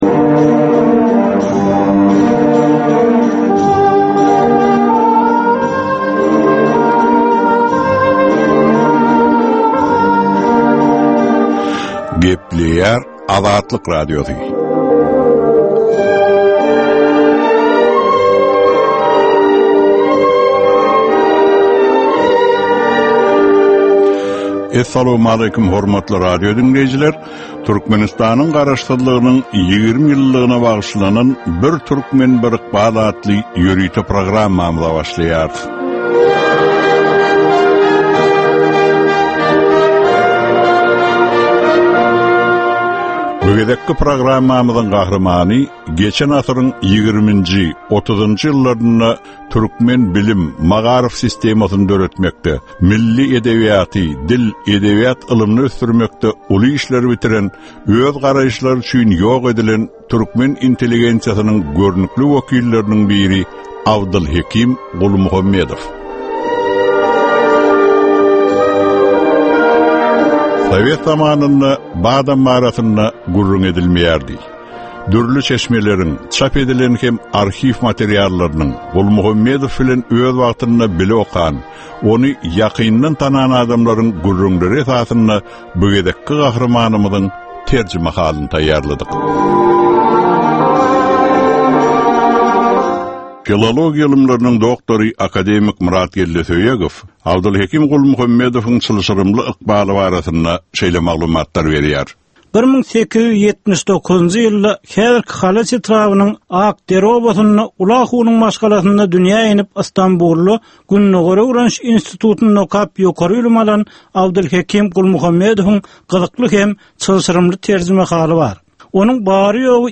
Türkmenistan we türkmen halky bilen ykbaly baglanyşykly görnükli şahsyýetleriň ömri we işleri barada ýörite gepleşik. Bu gepleşikde gürrüňi edilýän gahrymanyň ömri we işleri barada giňişleýin arhiw materiallary, dürli kärdäki adamlaryň, synçylaryň, bilermenleriň, žurnalistleriň we ýazyjy-sahyrlaryň pikirleri, ýatlamalary we maglumatlary berilýär.